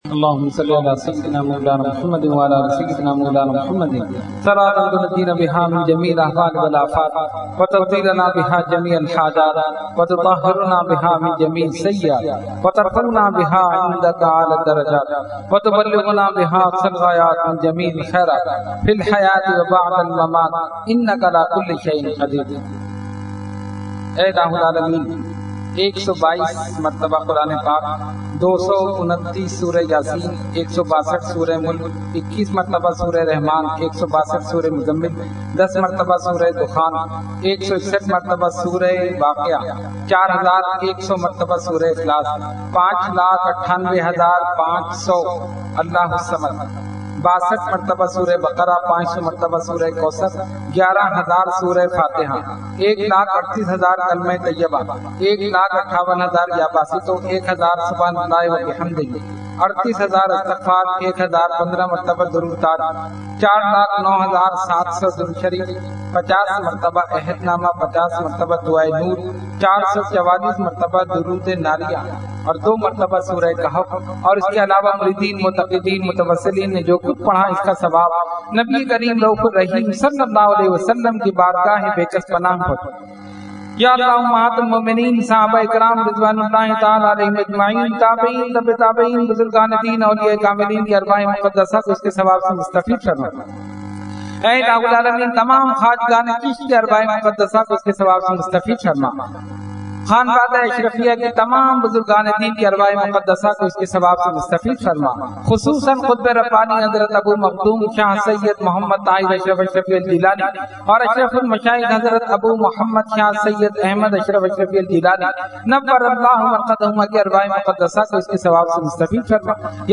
Dua – Salana Fatiha Ashraful Mashaikh 2013 – Dargah Alia Ashrafia Karachi Pakistan